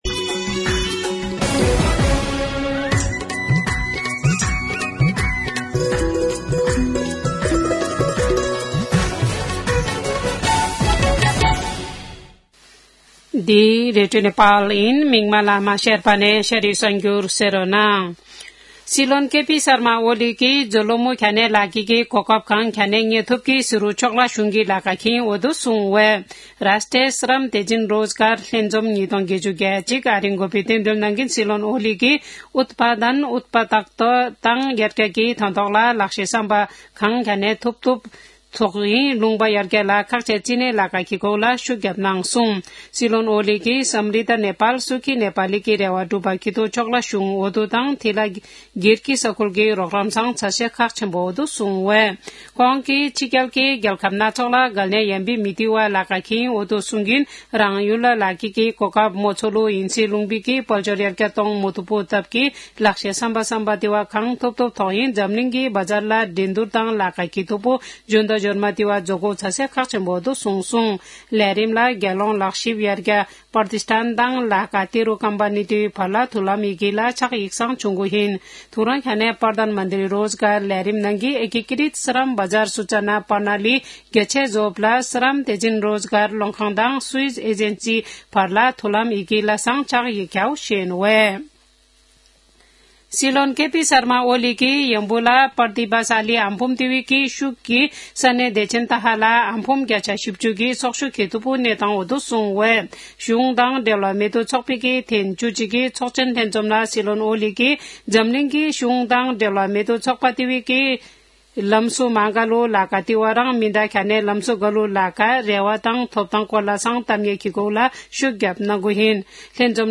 शेर्पा भाषाको समाचार : २७ फागुन , २०८१
Sherpa-News-26.mp3